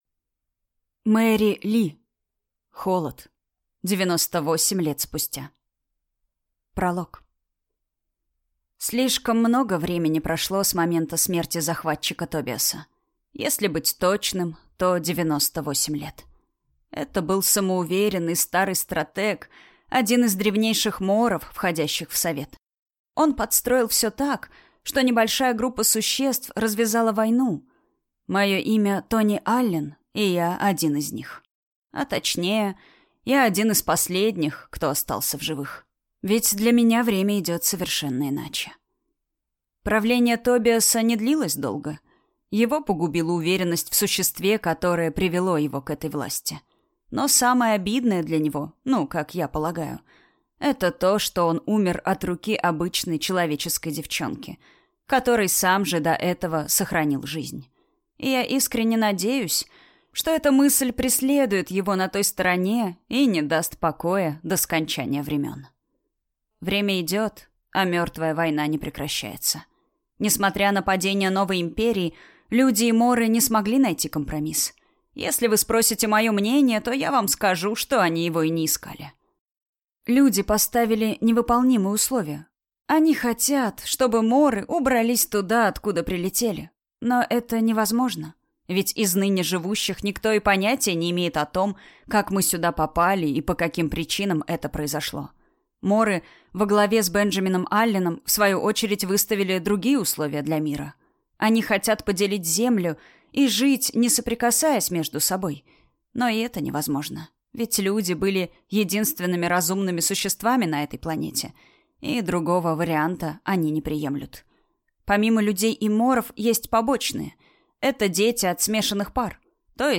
Аудиокнига Холод. 98 лет спустя | Библиотека аудиокниг
Прослушать и бесплатно скачать фрагмент аудиокниги